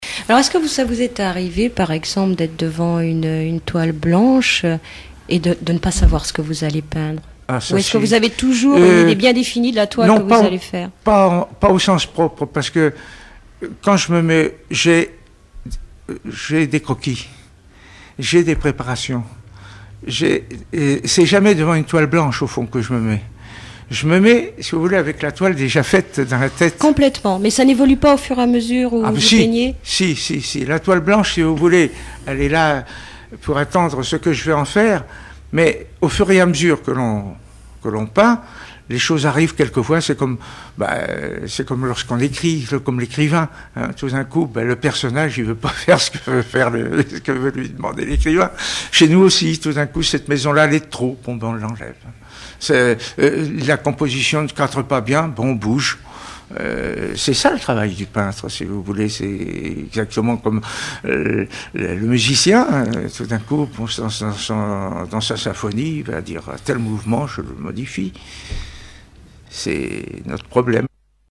Témoignage